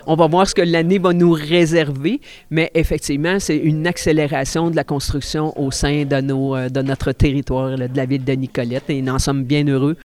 La mairesse suppléante, France Trudel, s’en réjouit.